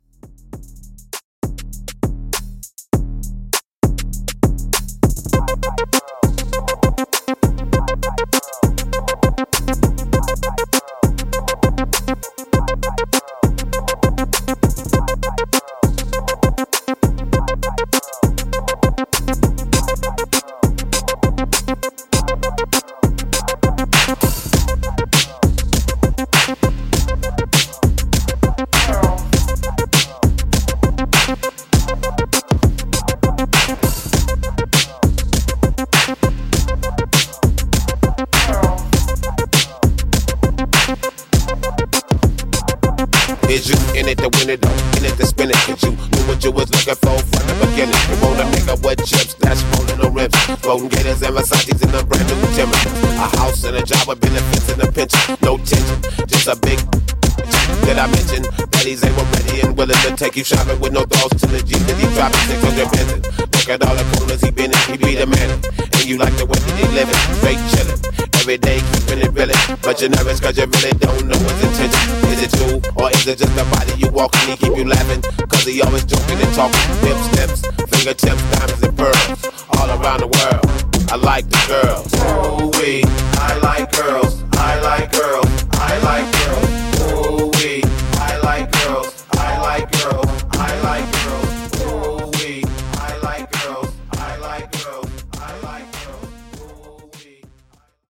Old School Redrum)Date Added